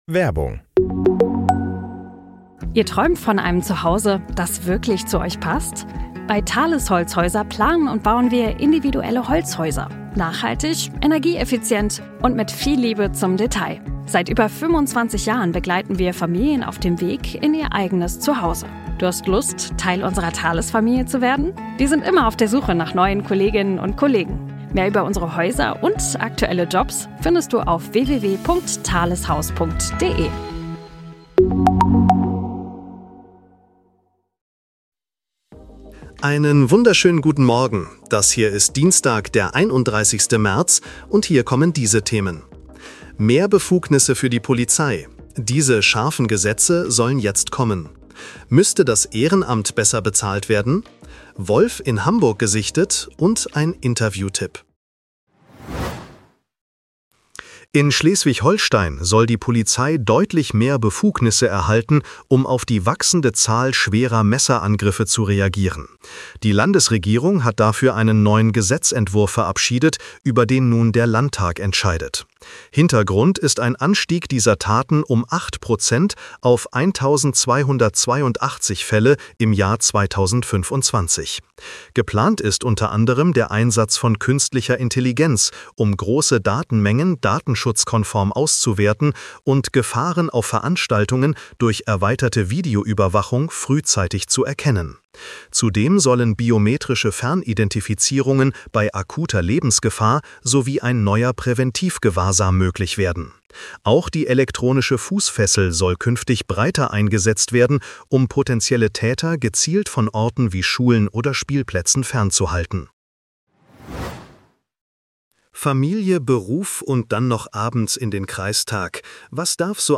Nachrichten-Botcast bekommst Du die wichtigsten Informationen aus